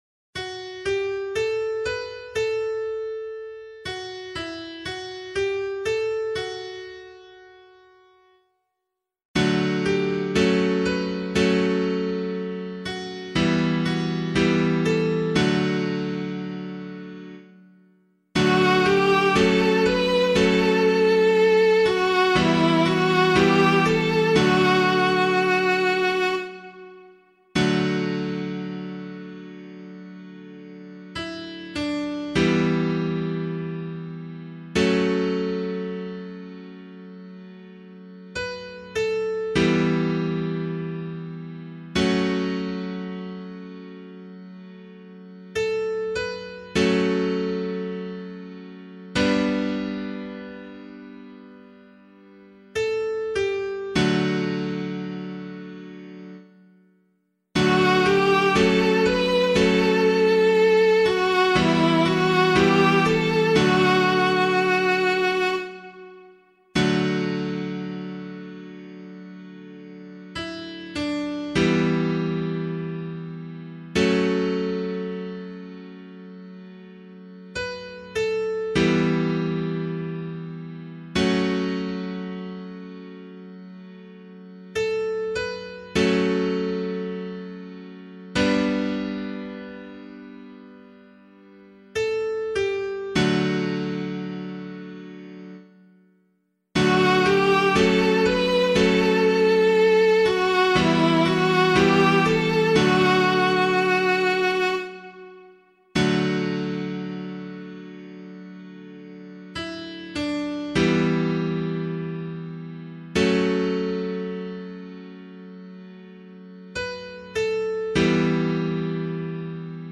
025 Easter 3 Psalm C [LiturgyShare 5 - Oz] - piano.mp3